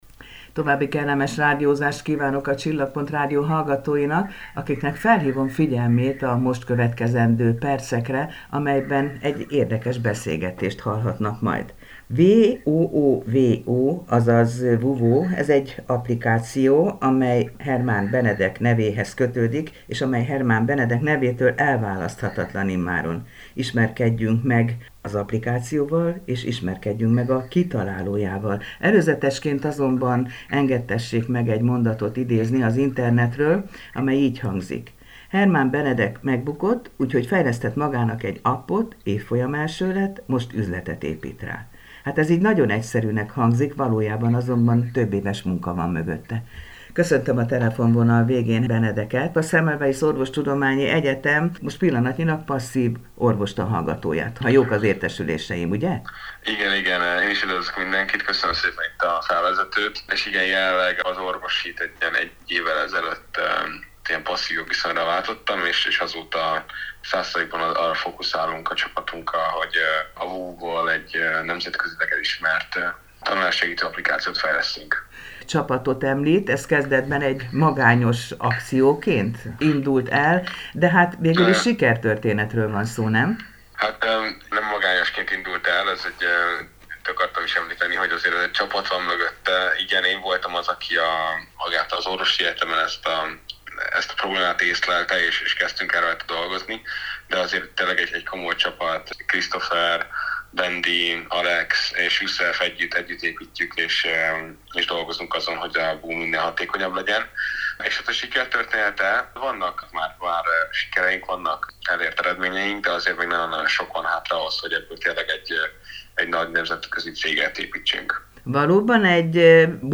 beszélgetett a Csillagpont Rádió műsorában.